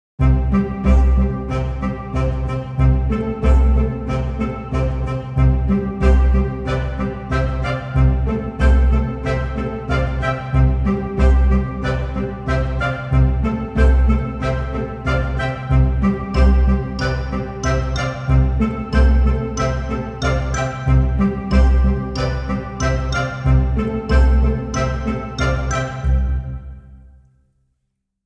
Music Ringtones